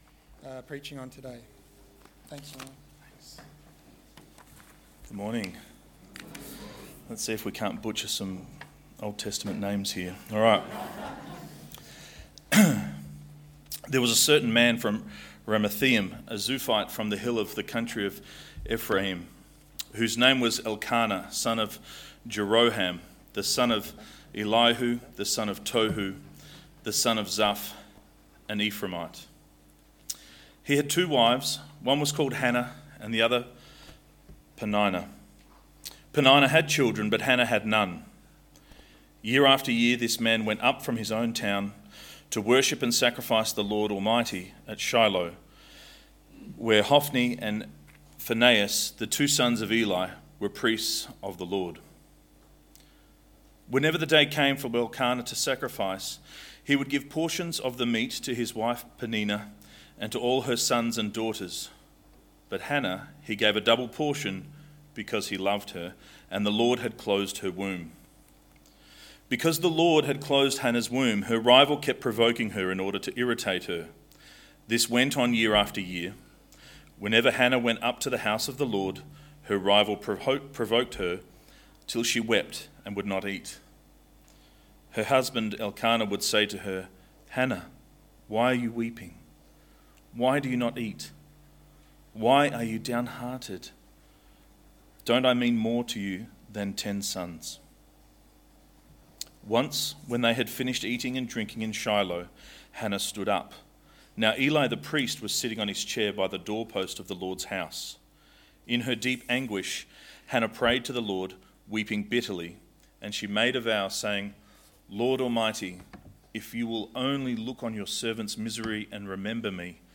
Samuel 1 - 2:10 Service Type: Sunday AM Bible Text: !